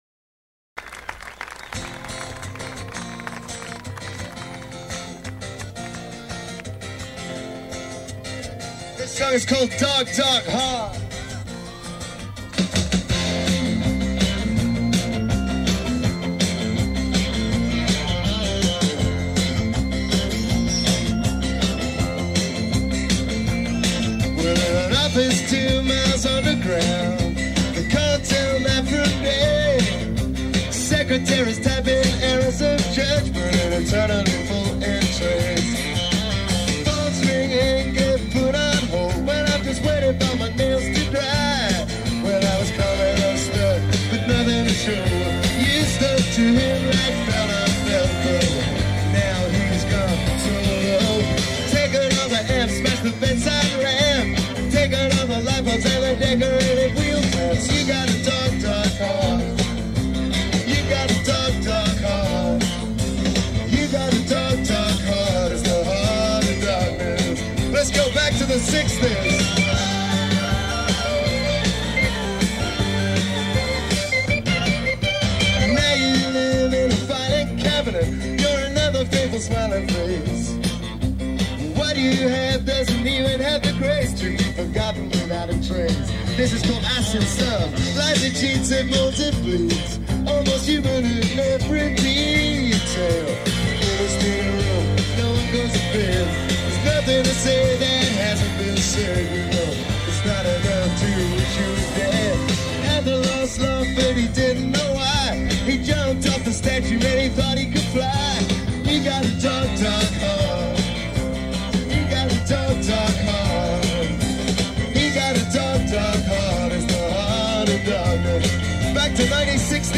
Live recordings
S.F., Union Square, 5-27-91: